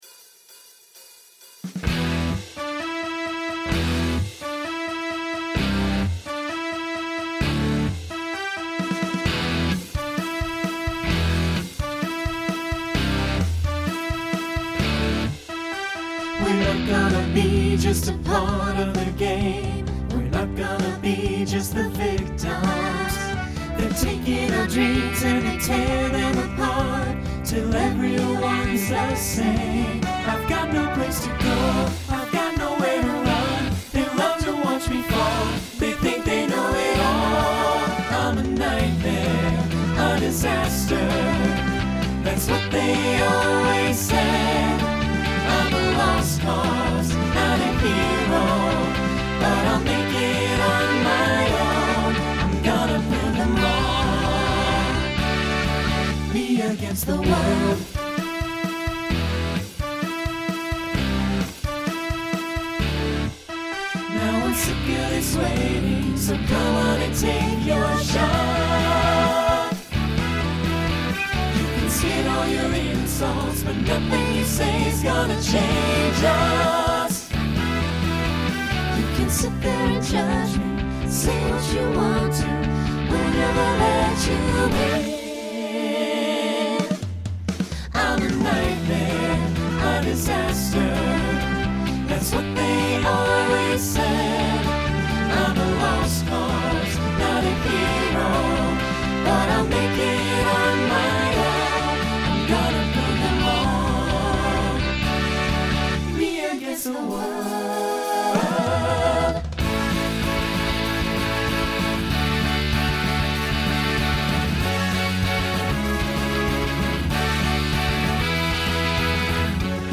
New SSA voicing for 2022.